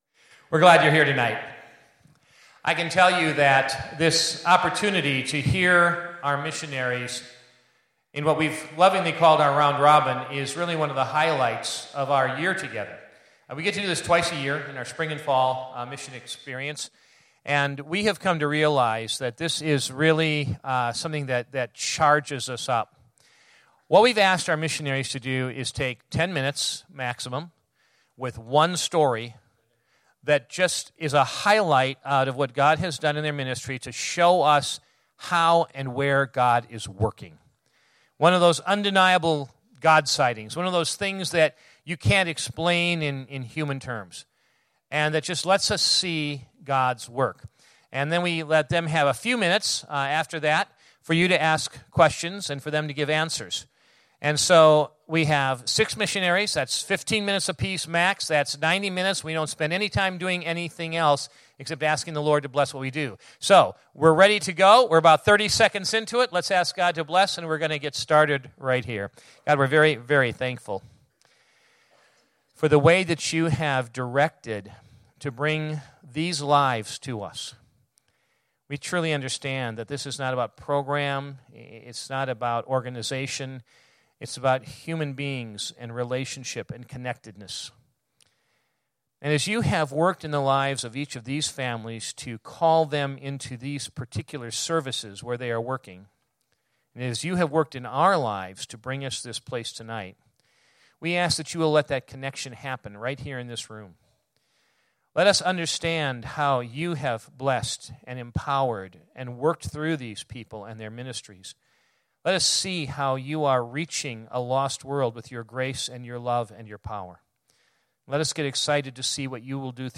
Messages Missionary Round Robin Series Global Impact Celebration By Missionaries Message Date April 24, 2016 Categories Sunday Evening Message Special Event Download Audio Short Stories Previous Back Next